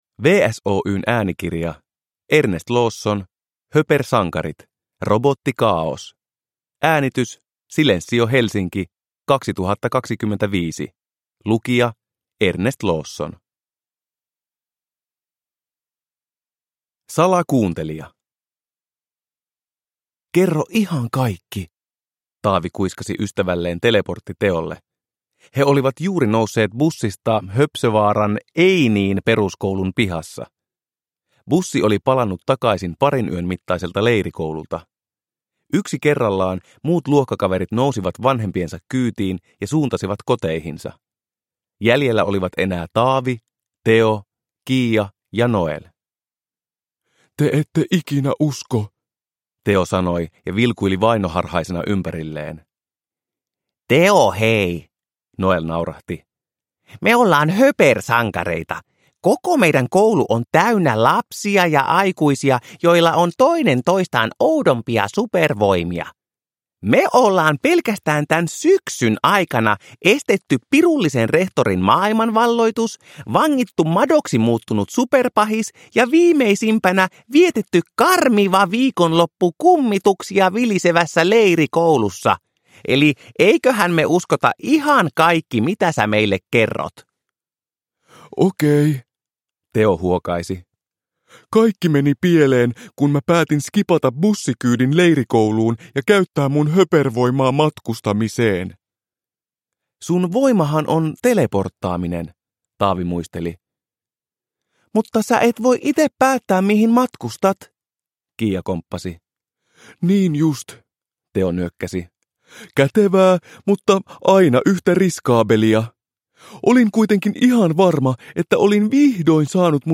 Höpersankarit 4: Robottikaaos (ljudbok) av Ernest Lawson